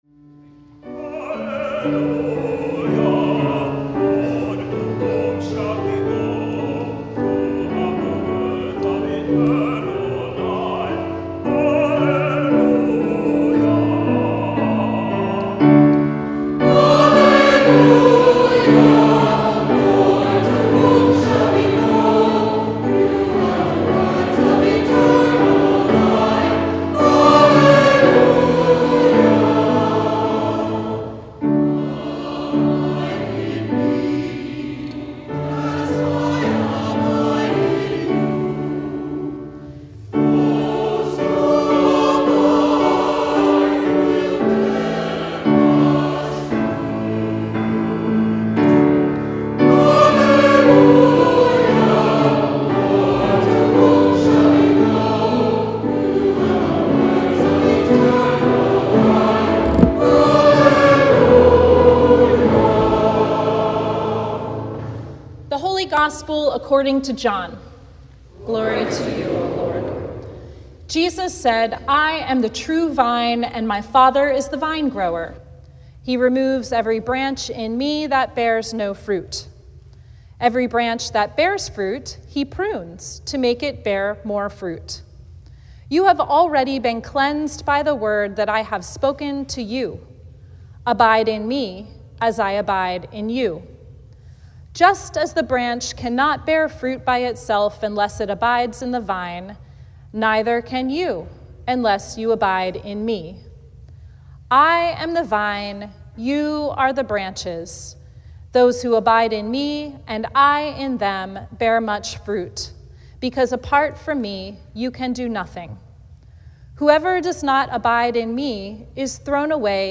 Sermon from the Fifth Sunday of Easter